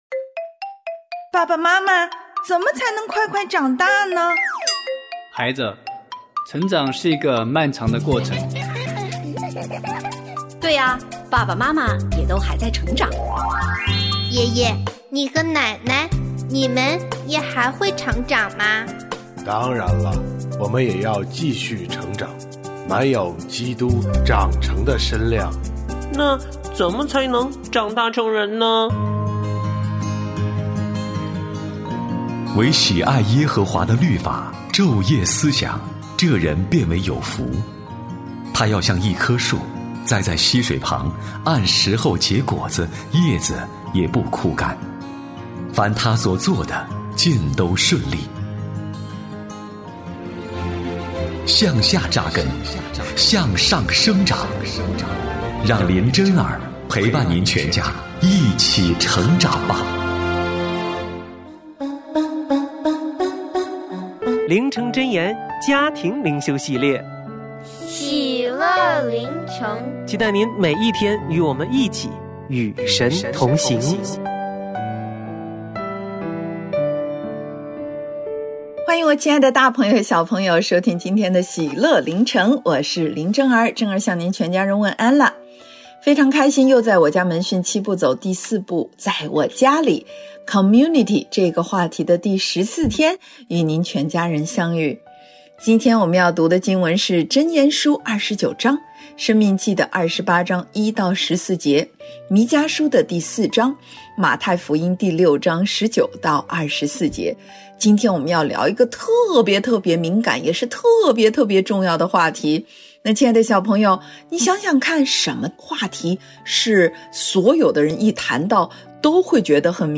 婚姻私塾：理所当然的事奉，浩瀚无垠的恩典──神秘嘉宾访谈录（8）